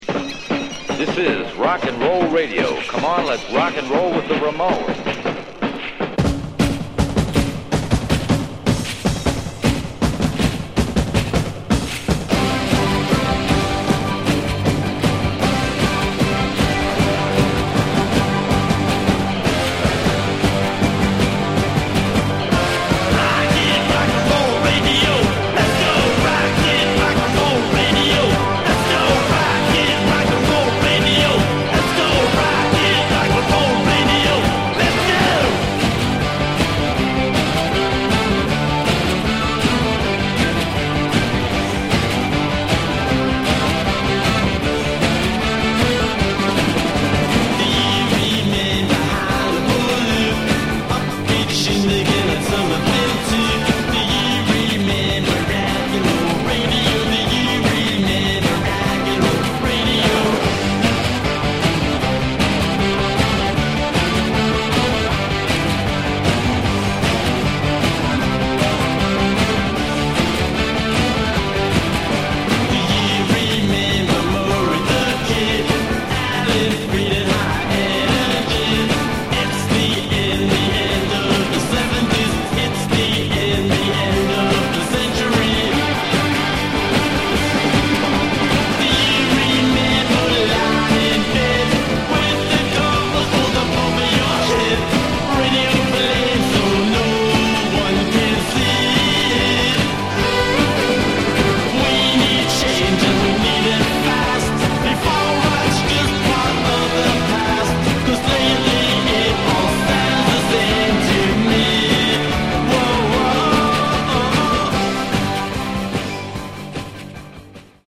Genre: Punk/Grunge